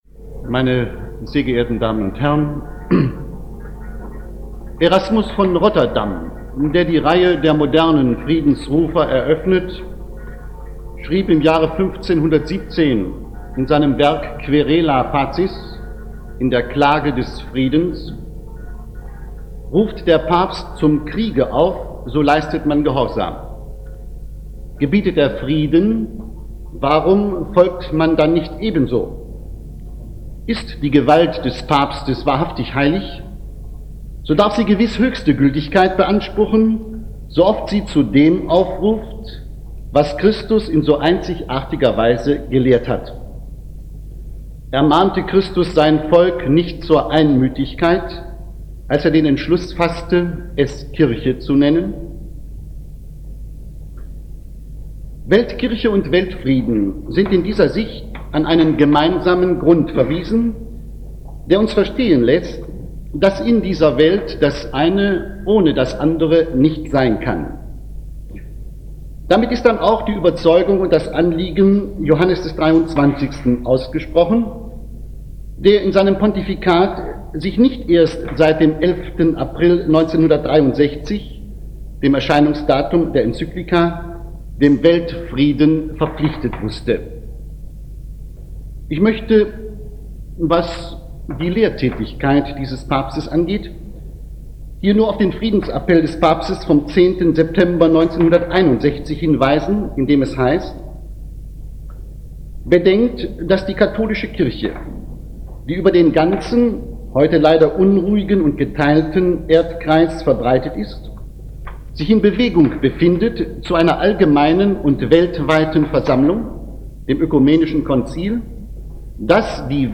Weltkirche und Weltfrieden nach der Enzyklika "Pacem in terris" - Rede des Monats - Religion und Theologie - Religion und Theologie - Kategorien - Videoportal Universität Freiburg